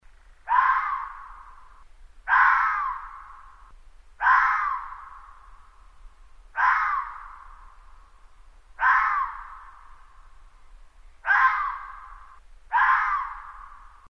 На этой странице собраны разнообразные звуки лисы: от реалистичных рычаний и тявканий до весёлых детских песенок.
Звук лисьего крика